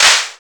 1712L CLP.wav